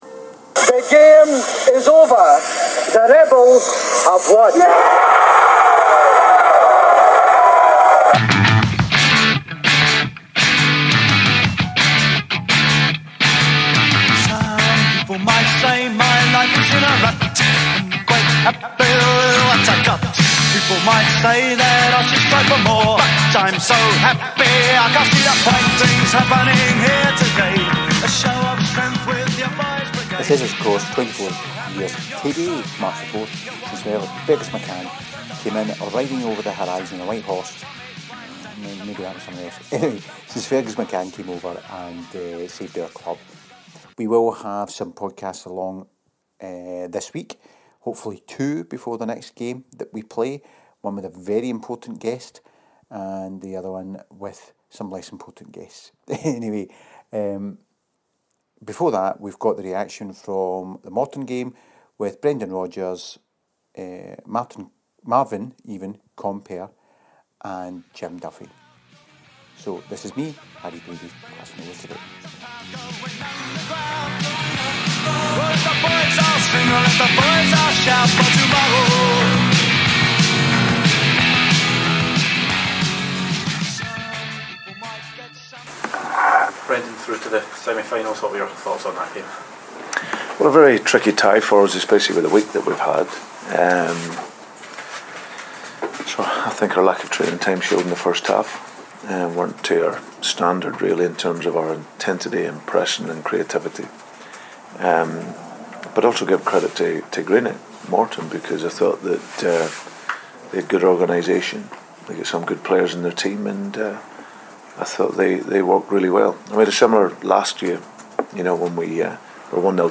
I’m not sure it comes across in the audio but it was clear in the press conference that Brendan was very unhappy with the first half performance and I understand he made that pretty clear in the dressing room at half-time.